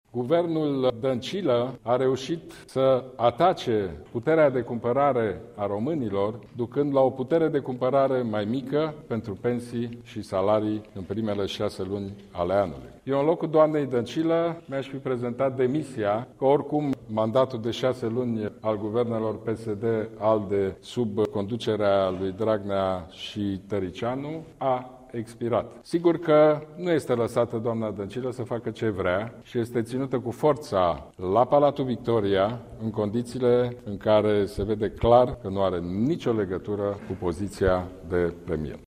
Preşedintele PNL, Ludovic Orban, a declarat la finalul unei şedinţe a conducerii liberale că actualul executiv a redus de fapt pensiile cu aproape un procent şi că în general, prin măsurile luate, guvernul Dăncilă nu a avut performanţe: